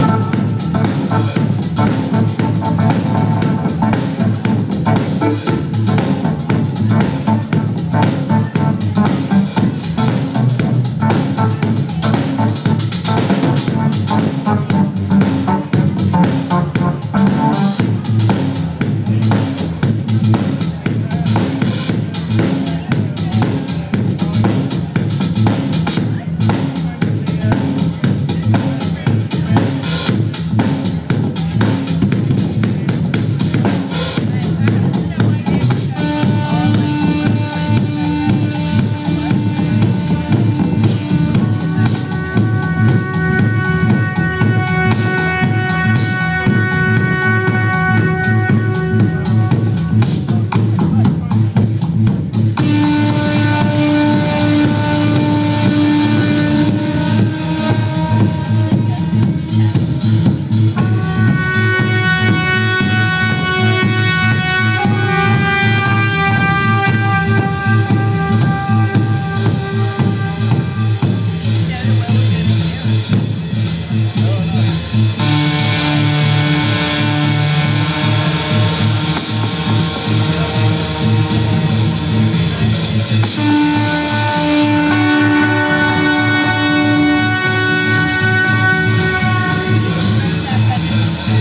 Live music at the Kazimer - liverpool, part of AND Festival